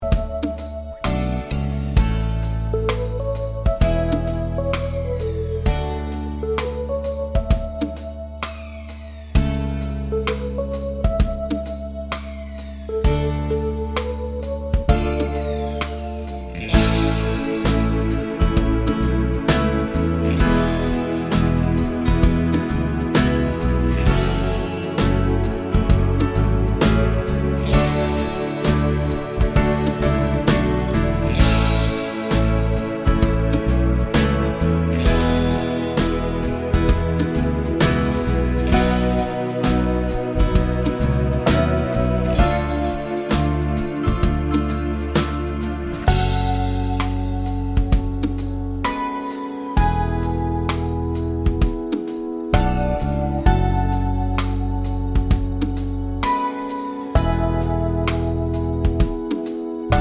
No Backing Vocals Without Intro Fade Pop